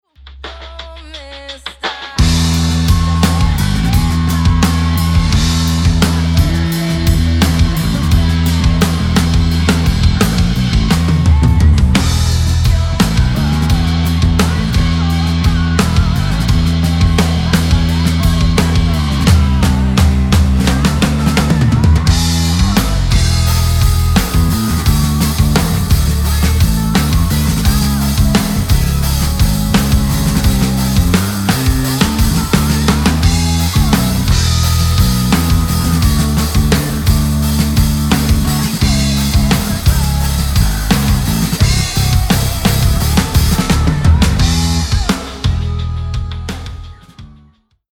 DrumBass
DrumBass.mp3